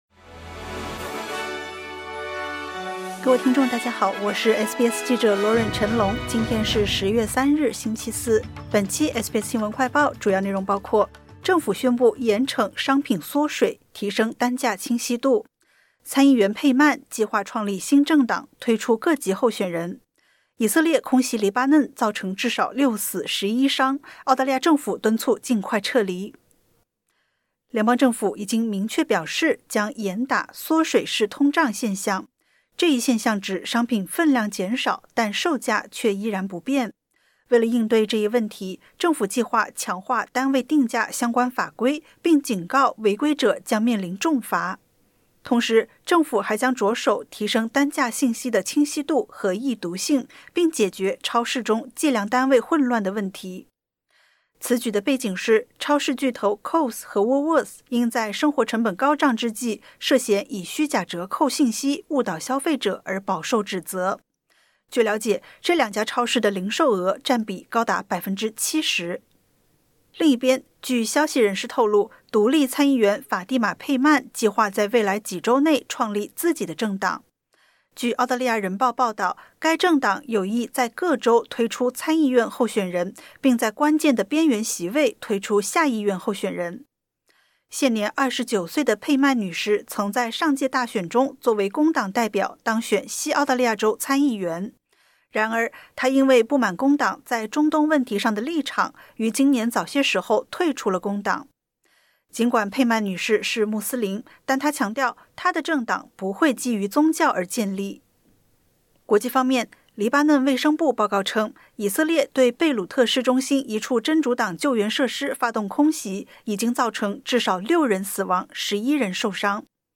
【SBS新闻快报】政府宣布严惩“缩水式通胀”提升单价清晰度